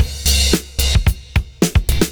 112CYMB07.wav